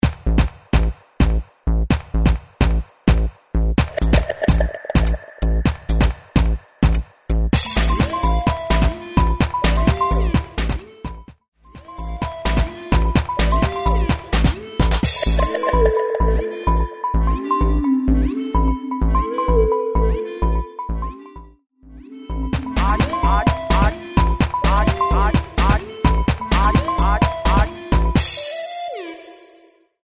128 BPM
Gqom